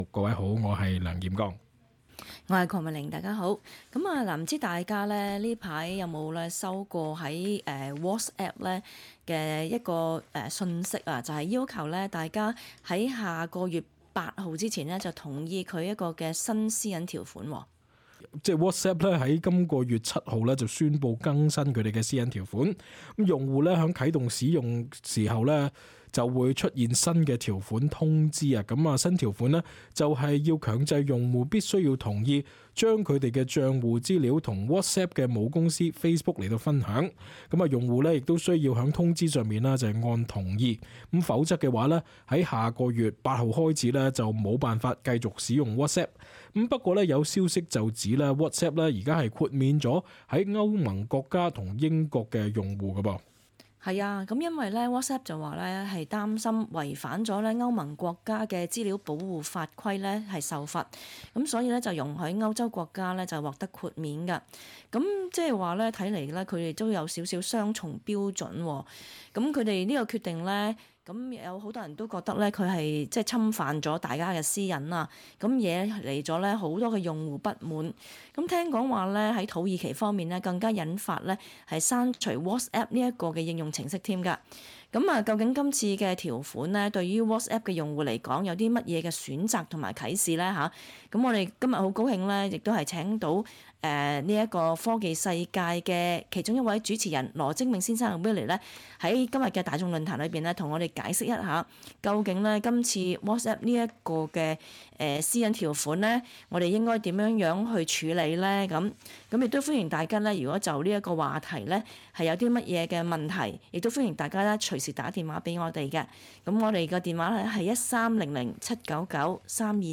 talkback_-_jan_14.mp3